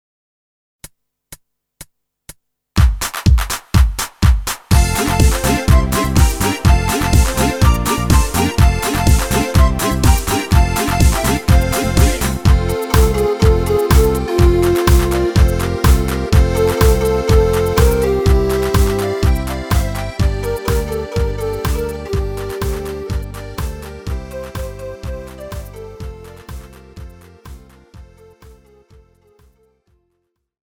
Rubrika: Pop, rock, beat
Nejnovější MP3 podklady